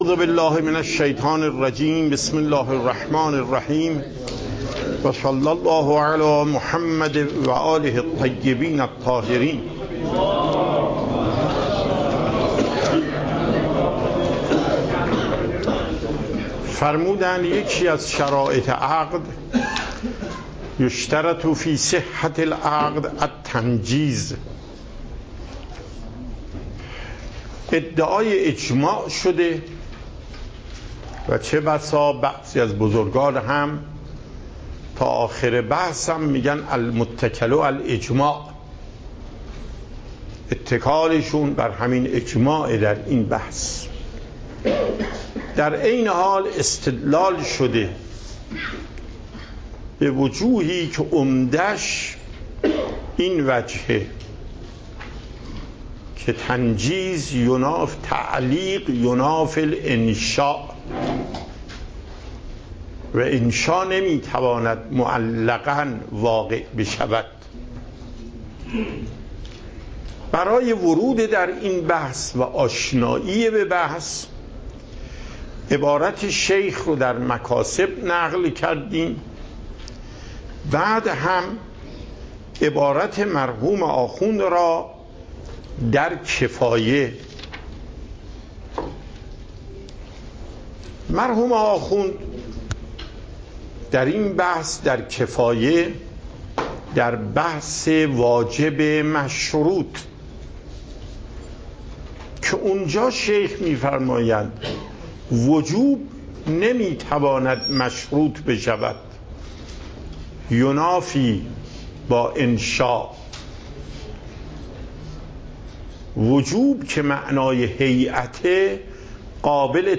صوت و تقریر درس پخش صوت درس: متن تقریر درس: ↓↓↓ تقریری ثبت نشده است.
درس فقه آیت الله محقق داماد